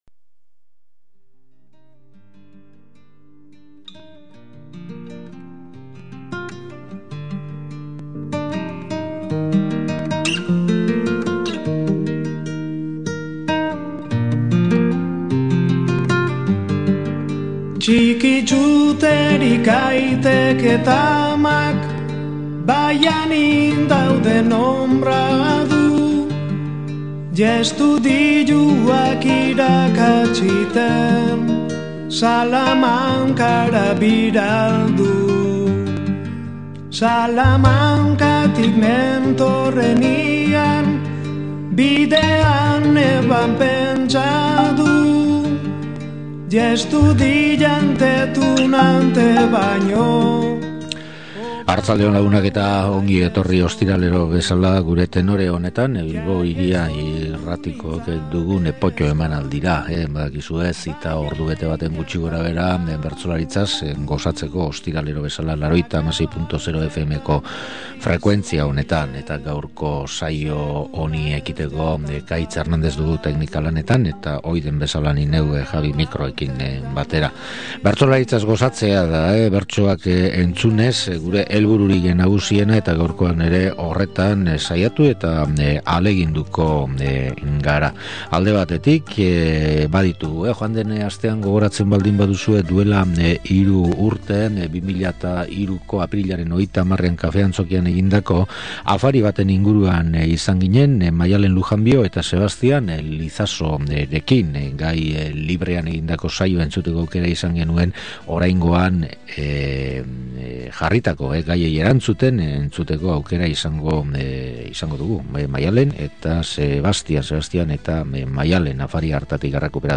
Bestalde, aurreko saioan hasitako lanari jarraikiz, 2003ko apirilaren 30ean Bilboko Kafe Antzokian egindako bertso afarian entzun ziren bertso ale batzuk ere bildu dizkigu.